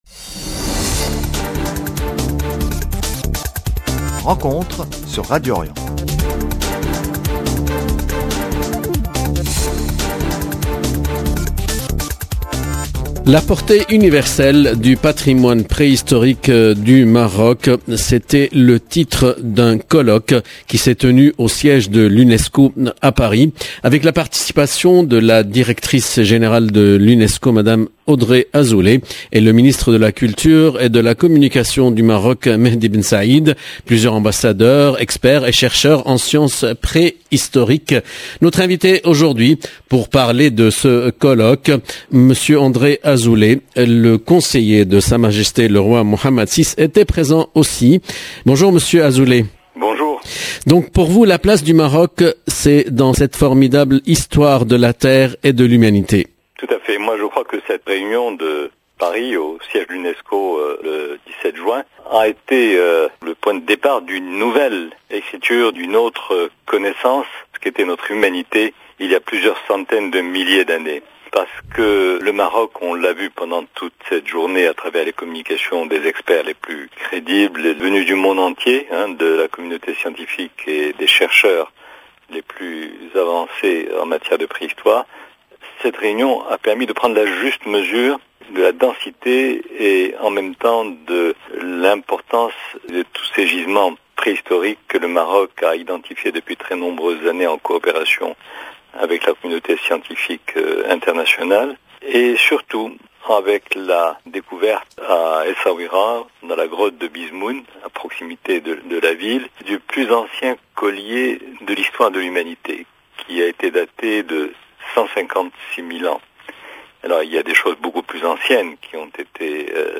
L’invité de Rencontre, Le conseiller du roi du Maroc ,André Azoulay, a participé à ce colloque et nous parle de la valeur de ce patrimoine 0:00 9 min 48 sec